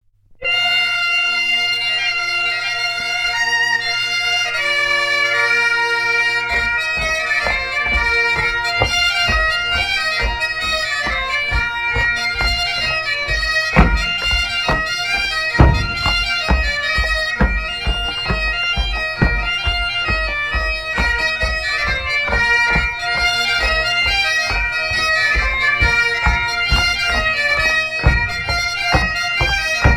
Usage d'après l'analyste gestuel : danse
Pièce musicale éditée